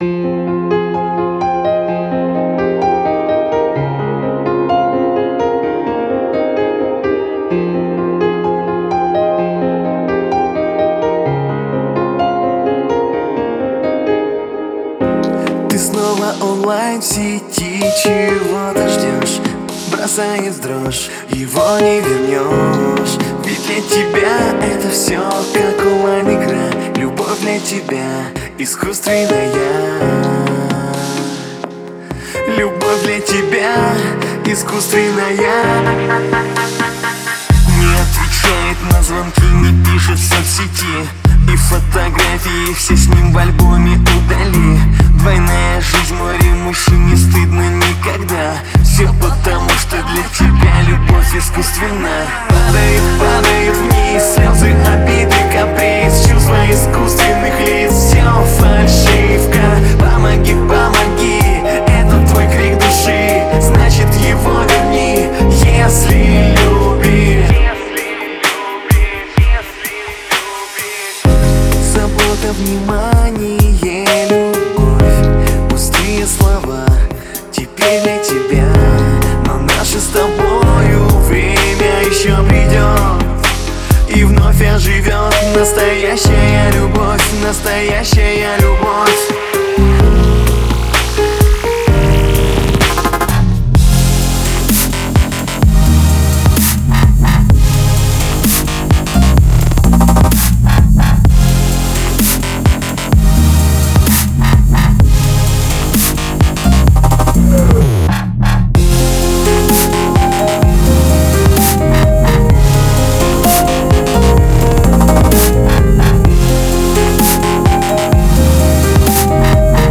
Категория: Электро музыка » Дабстеп